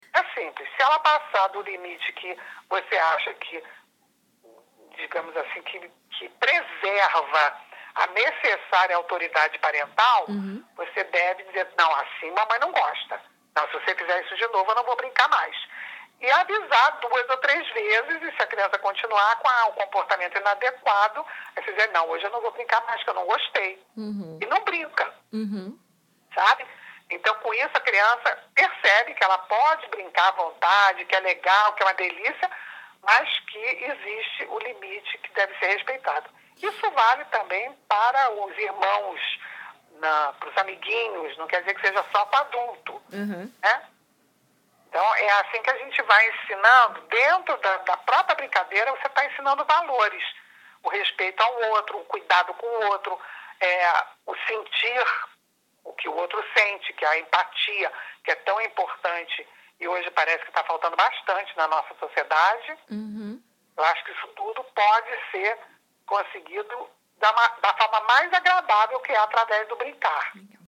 Entrevista especial com Tania Zagury- FOTO TANIA